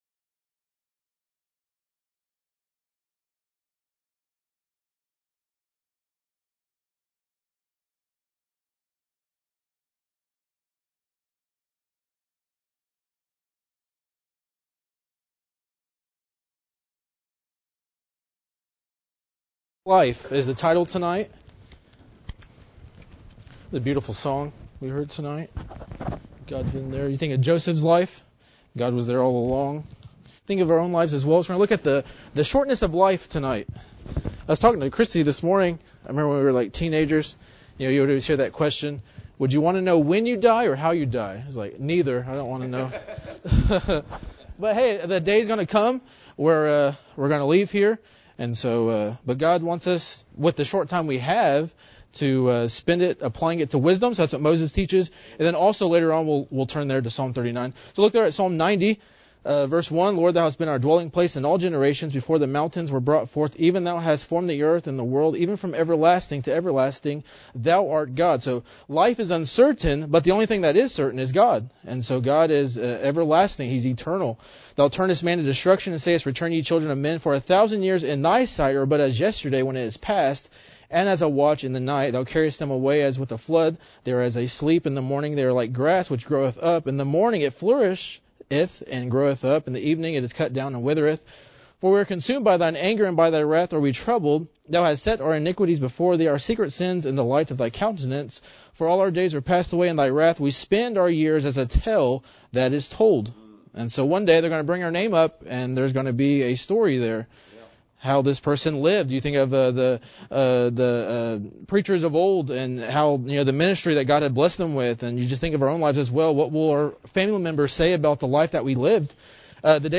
The sermon challenged listeners to live intentionally for God, cherish each day, and trust Him with their lives.